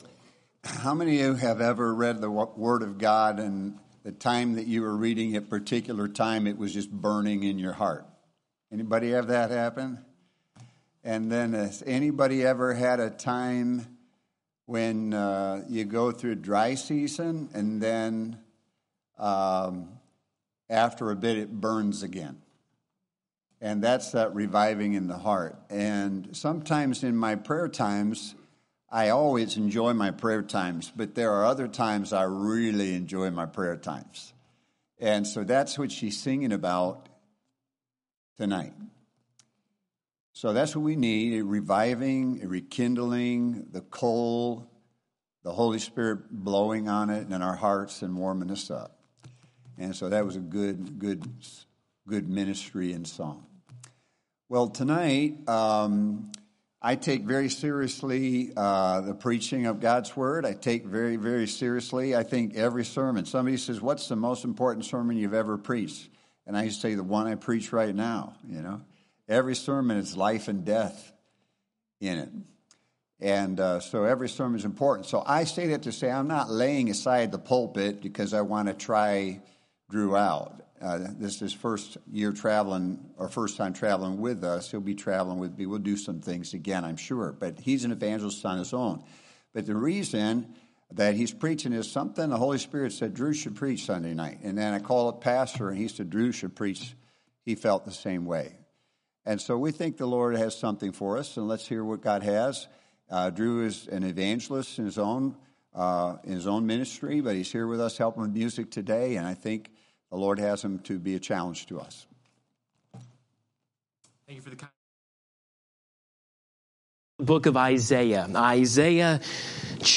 Sunday Evening Revival Service